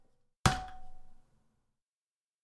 #2 Metal Bang
bang Bonk hit impact knock metal metallic percussion sound effect free sound royalty free Memes